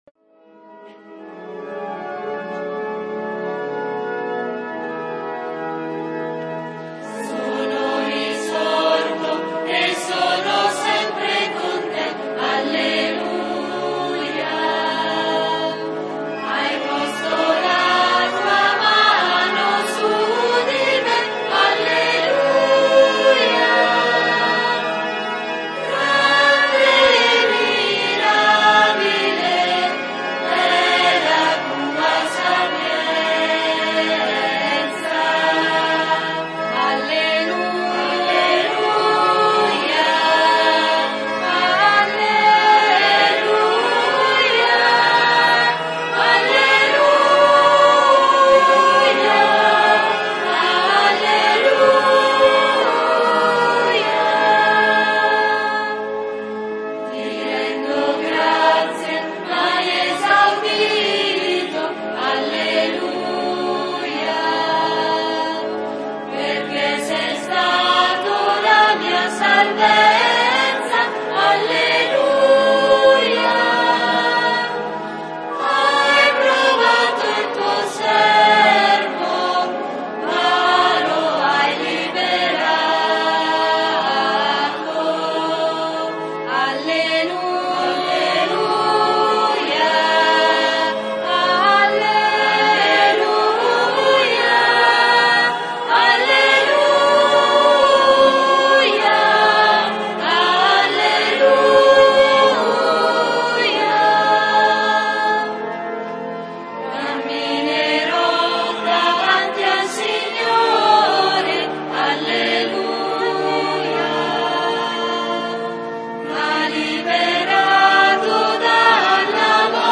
SABATO SANTO -Celebrazione della Resurrezione del Signore
canti: